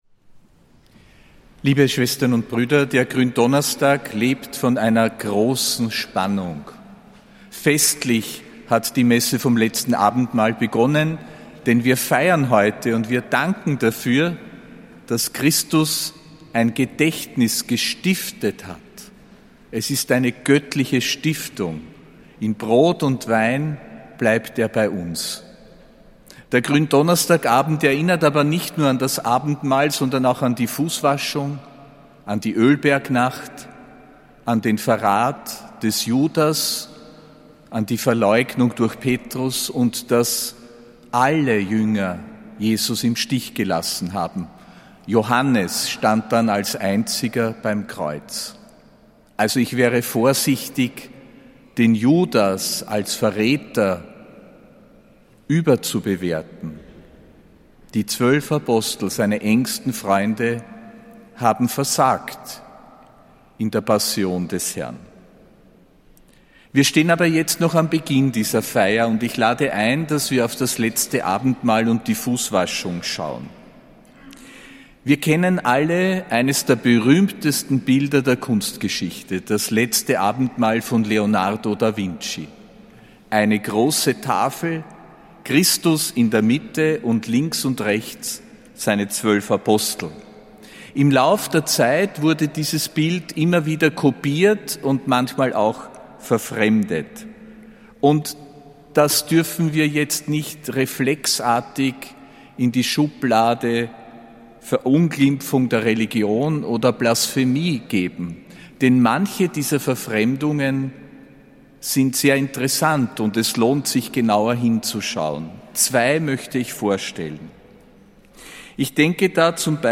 Predigt von Erzbischof Josef Grünwidl zum Gründonnerstag, am 2.